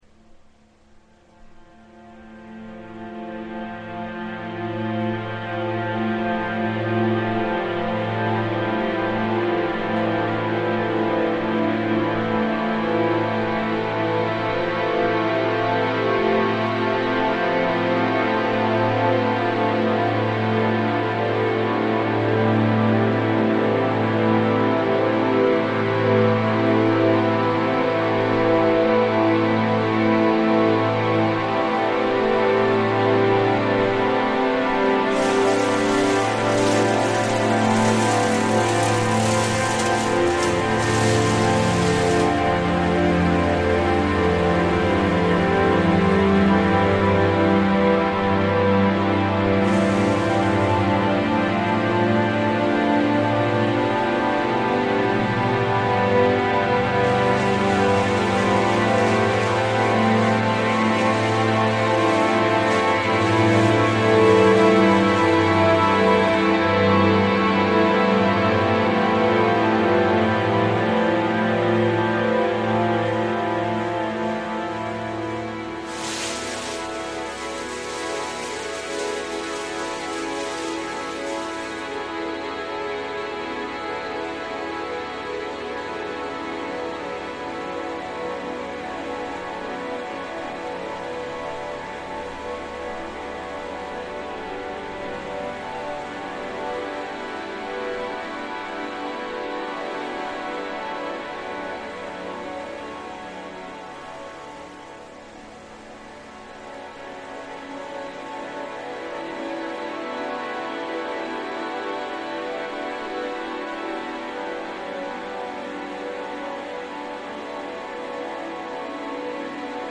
Tree disposal in Greenwich Park reimagined